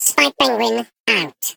Sfx_tool_spypenguin_vo_selfdestruct_06.ogg